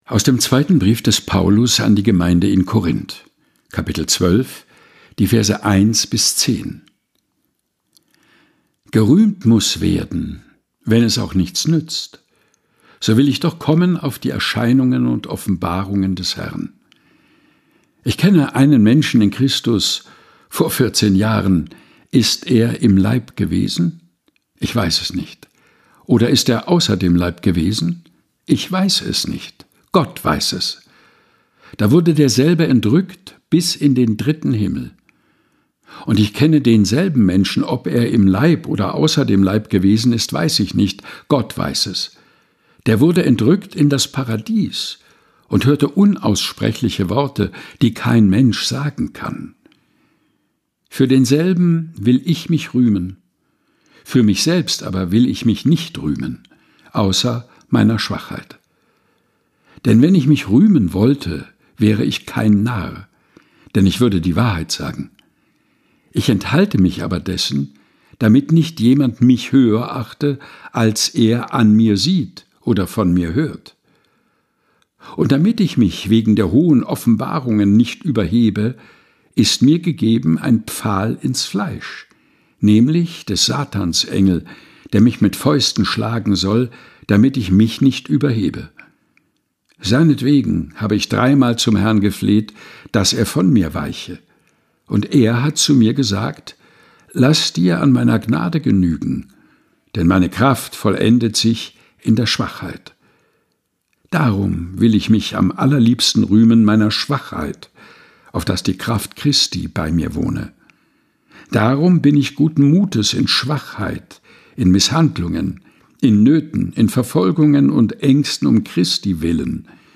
Predigttext zum 5.Sonntag nach Trinitatis des Jahres 2024.